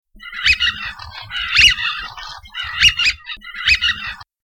Canard siffleur
Anas penelope
siffleur.mp3